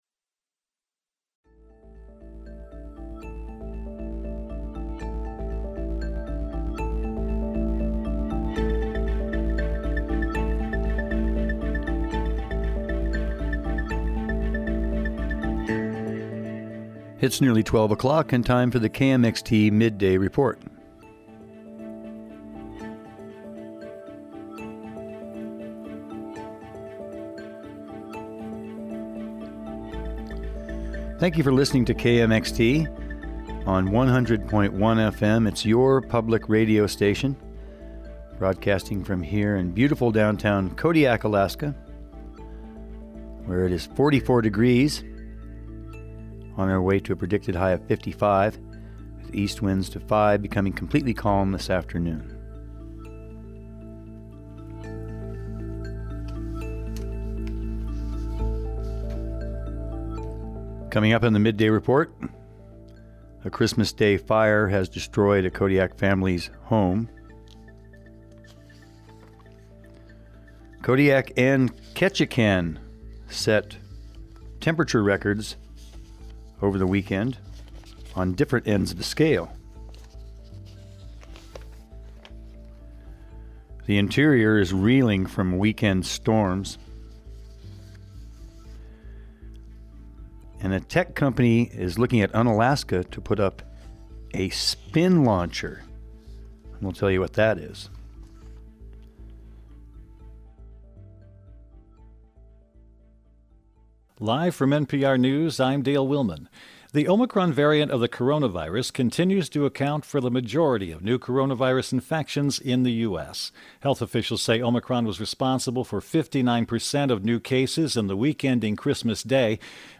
KMXT Staff December 28, 2021 News, Newscasts, Newsflash